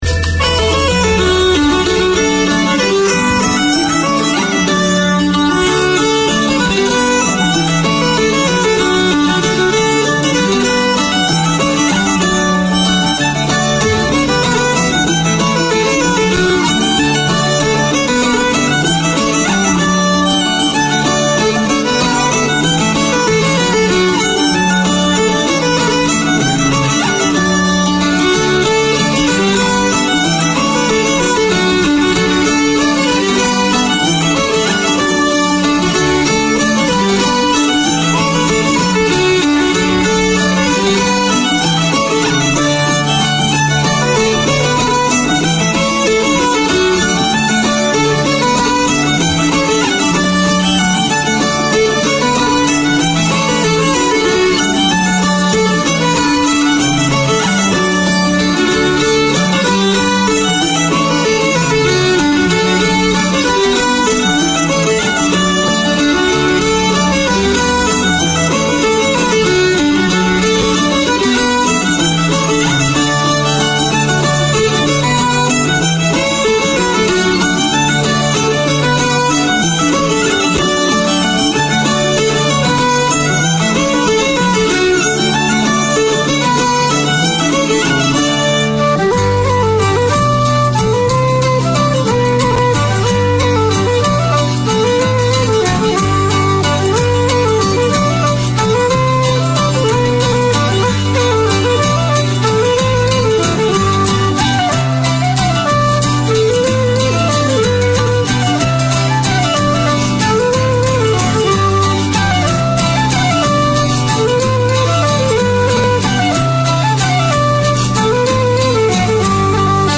Featuring a wide range of Irish music and the occasional guest, including visiting Irish performers, politicians, sports and business people.
Ireland Calling 10:00pm WEDNESDAY Community magazine Language